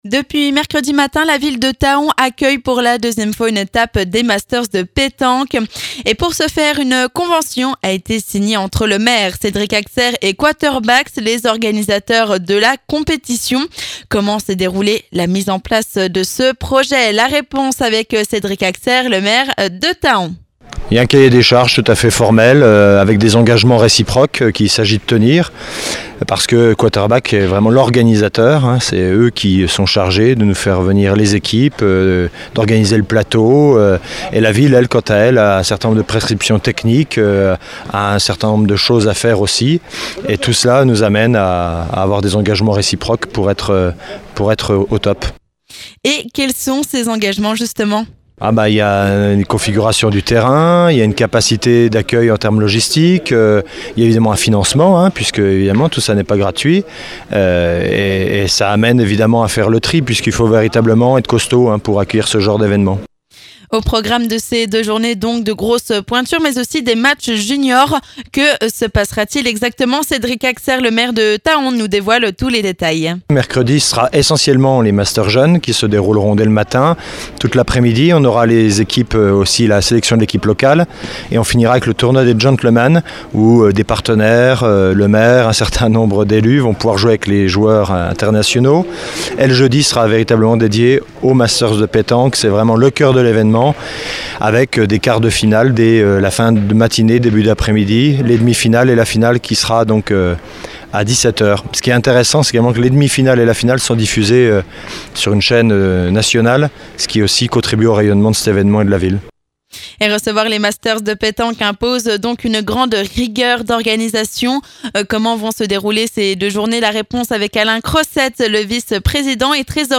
Nous sommes allés à la rencontre du maire de Thaon